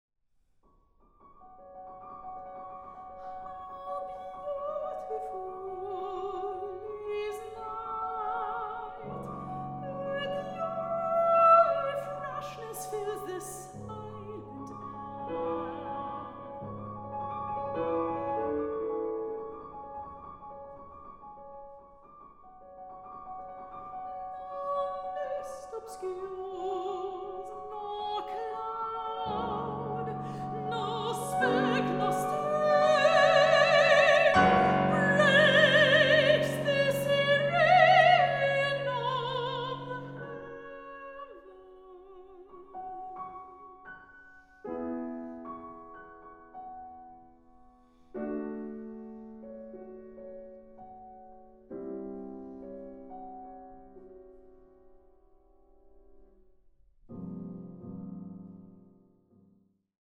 Sopran
Klarinette
Klavier